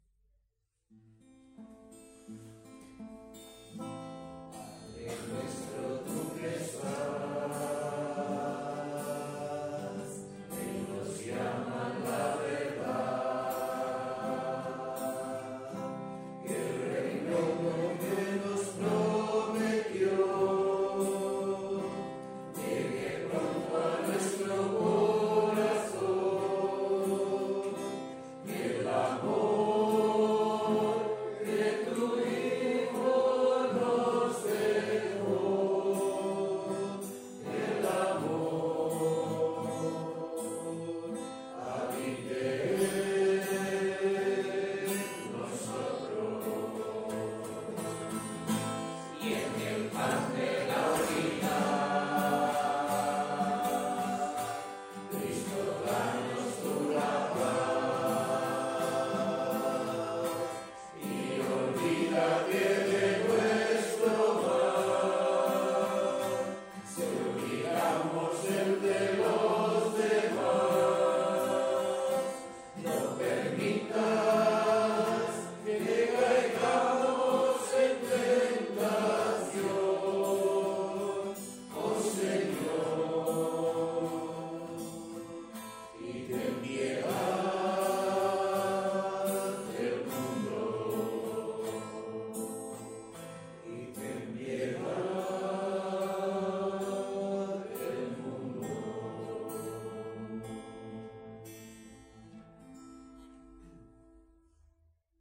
Parròquia Maria Auxiliadora - Diumenge 30 de març de 2025
Vàrem cantar...